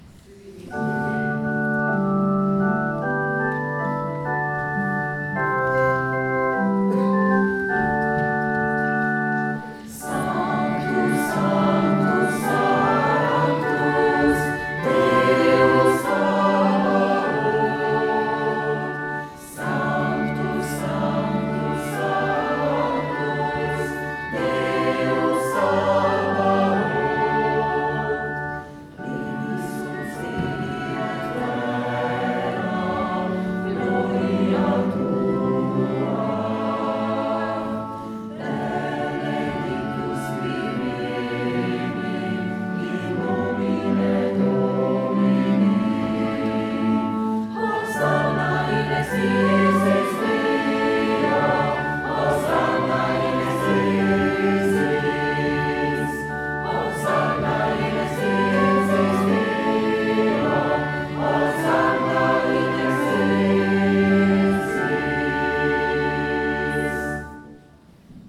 ZBOR ZAKONCEV